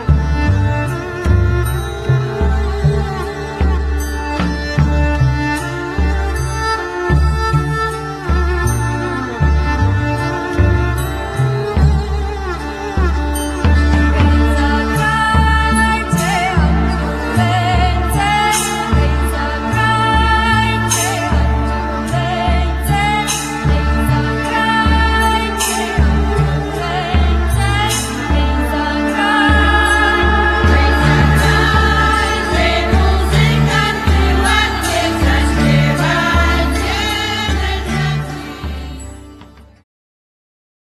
śpiew, cymbały
śpiew, fidel płocka, skrzypce
bęben obręczowy, talerz polny
baraban, werbel, ksylofon